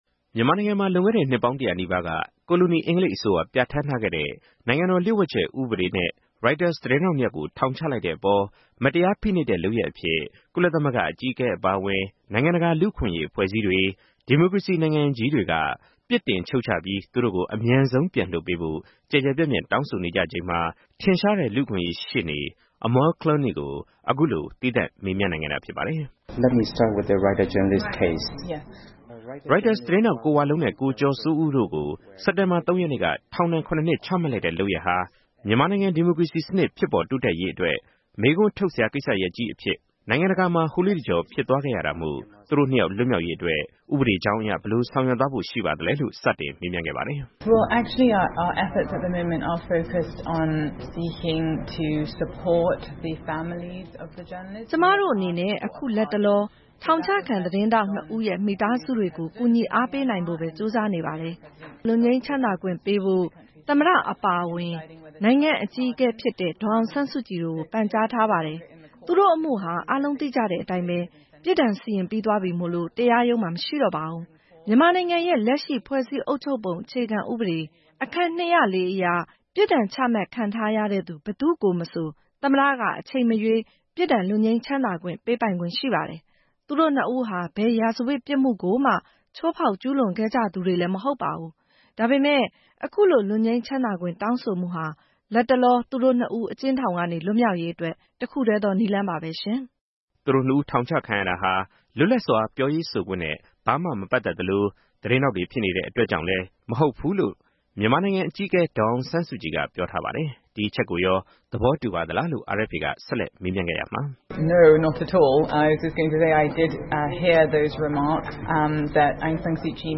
နိုင်ငံတကာ လူ့အခွင့်အရေး ရှေ့နေ အမားလ်ကလွန်းနီ နဲ့ တွေ့ဆုံမေးမြန်းချက်